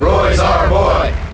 File:Roy Cheer International SSBM.ogg
Roy_Cheer_International_SSBM.ogg